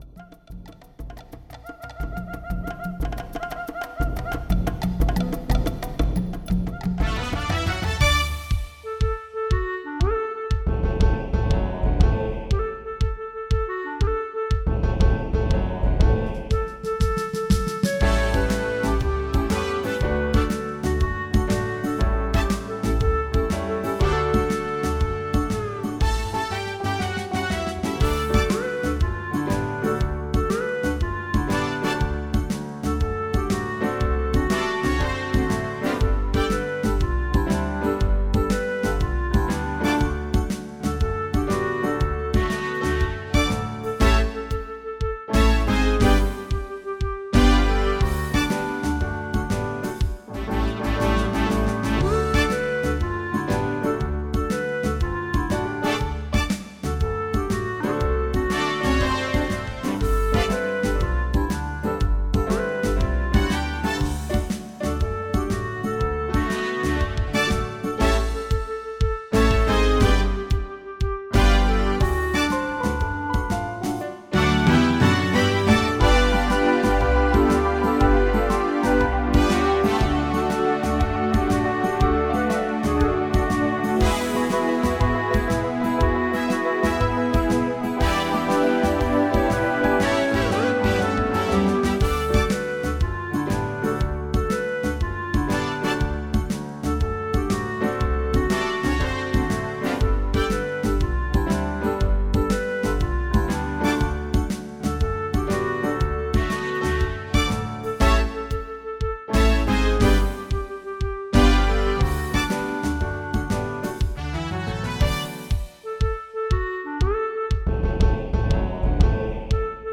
Brano originale (in re)